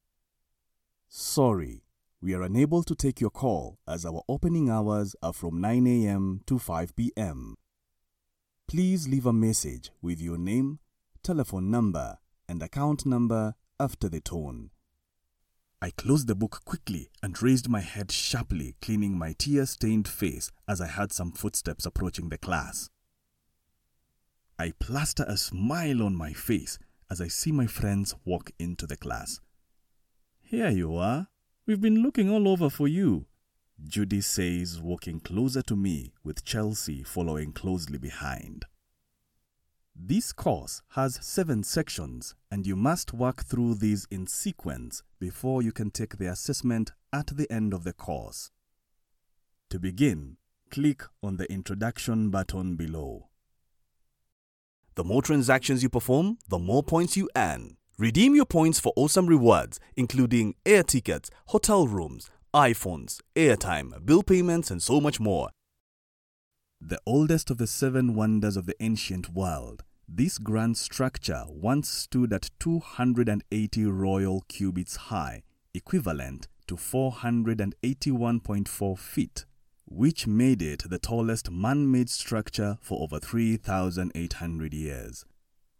English (African)
Mature
Warm
Funny